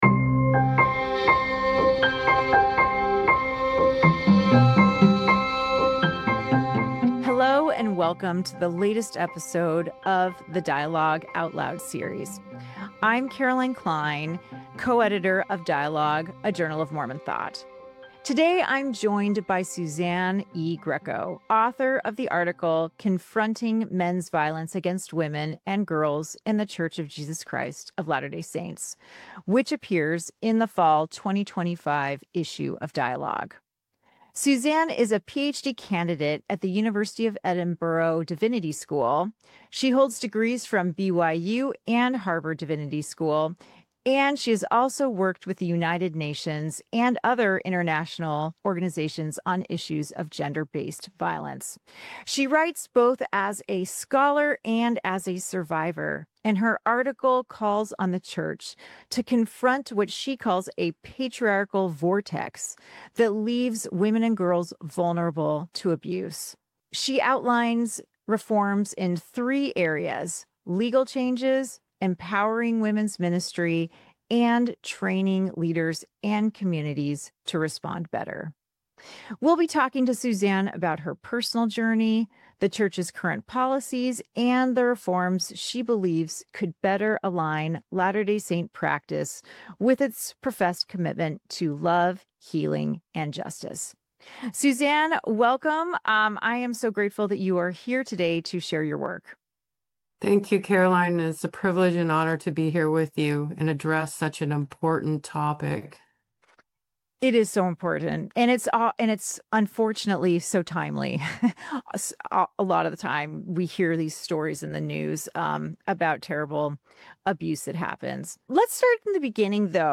Confronting Men’s Violence Against Women in the LDS Church: A Conversation